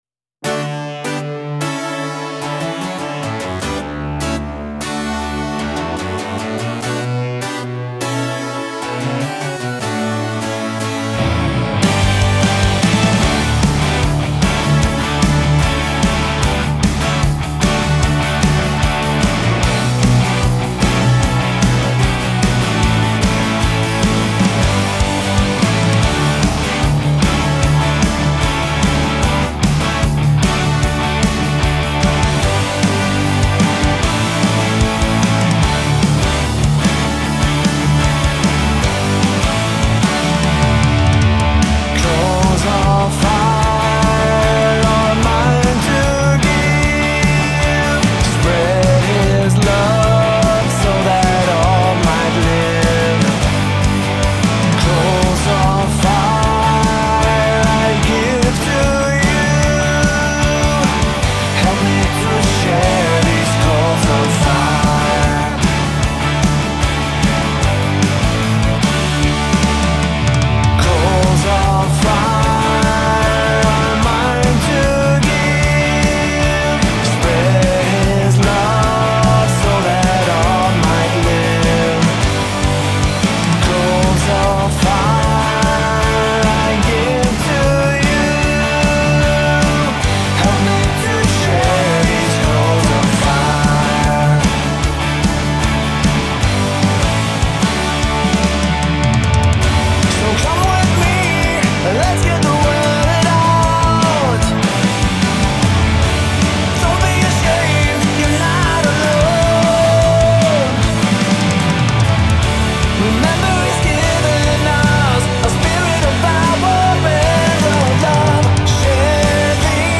Christian rock